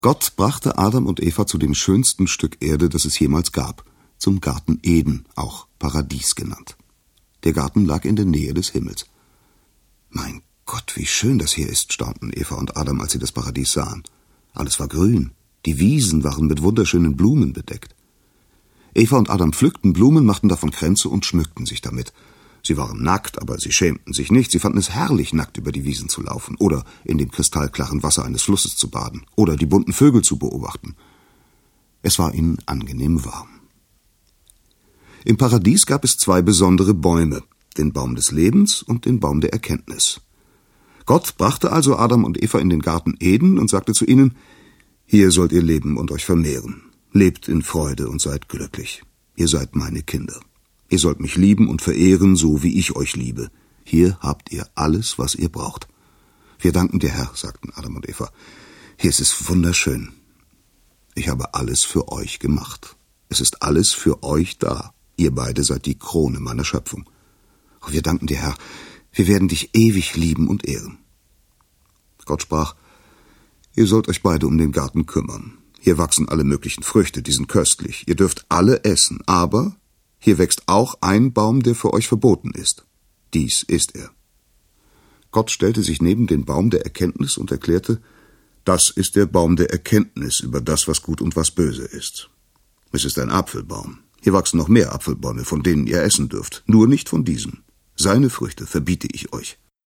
Hörbuch: Die Bibel.